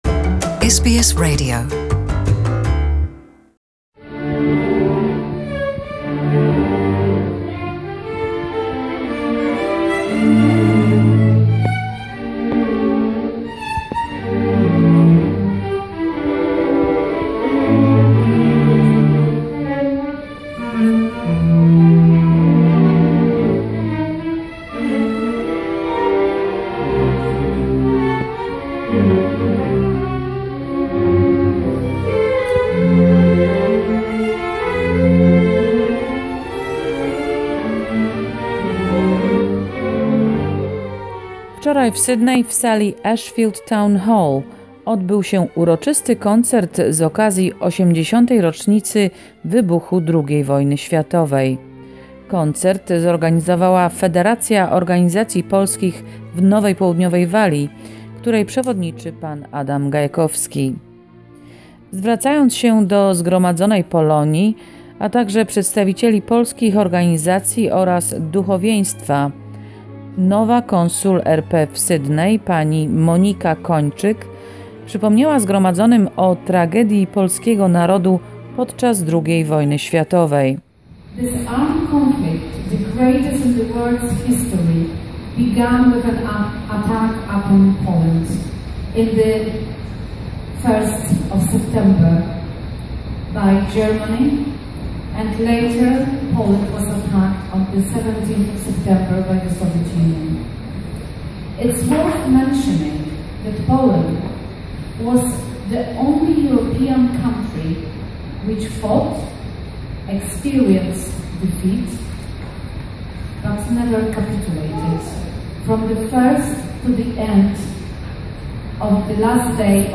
WWII - 80th Anniversary Commemerative Concert